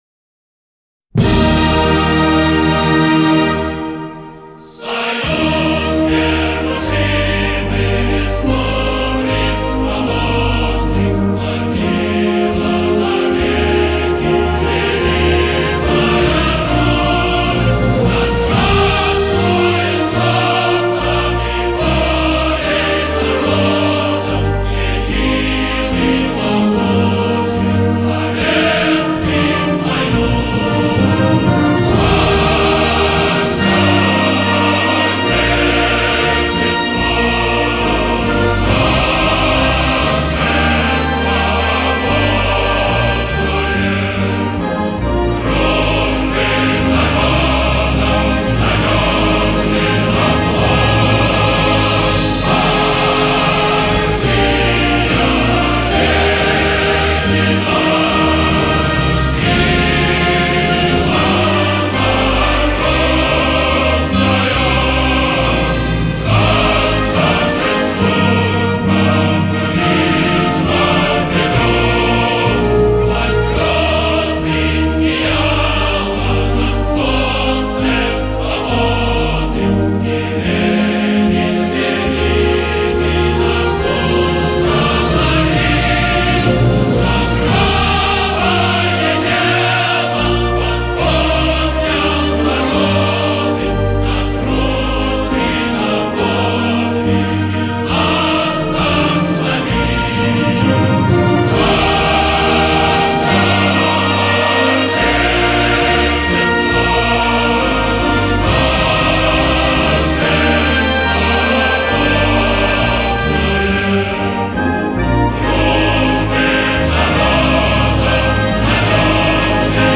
20 موزیک فوق العاده ازگروه همخوانی اتحاد جماهیر شوروی
01_-_national_anthem_of_the_ussr.mp3